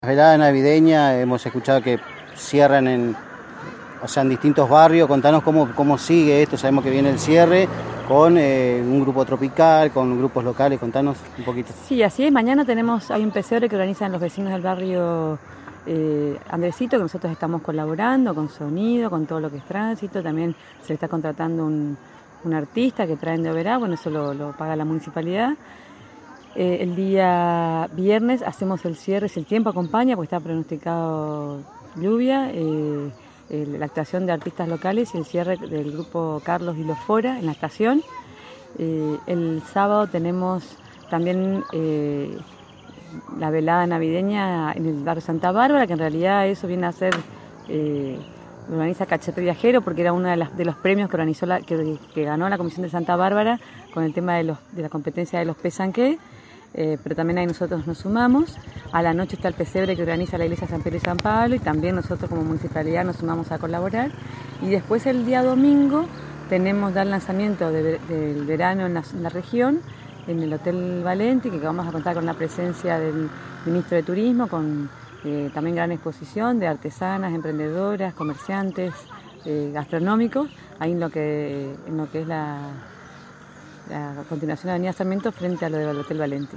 La Intendente de Apóstoles dialogó con Radio Estilo 90.3mhz sobre lo que se viene para las próximas noches de las Veladas Navideñas en Apóstoles y también nos contó sobre el cierre de etapa de eventos.